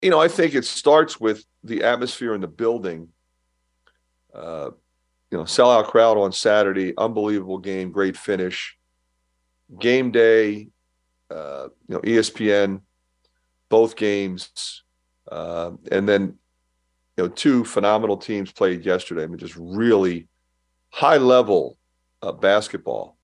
Iowa coach Fran McCaffery.